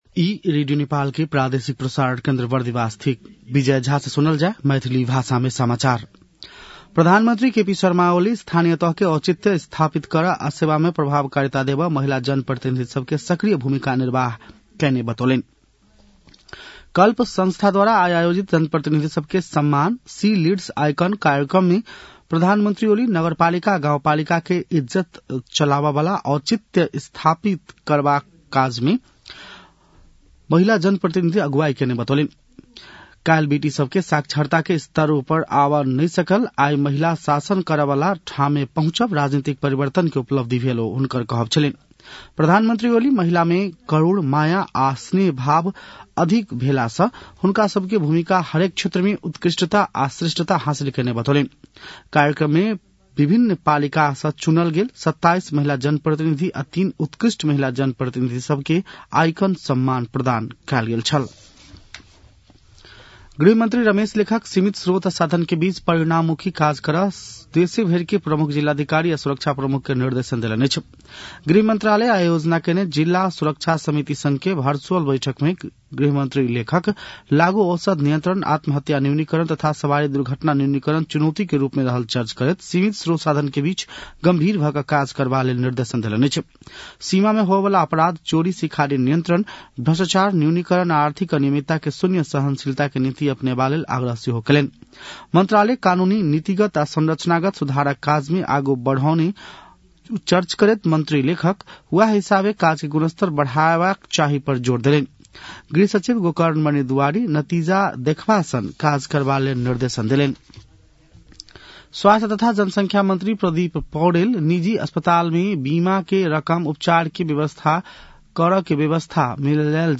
मैथिली भाषामा समाचार : २० पुष , २०८१
6-pm-maithali-news-9-19.mp3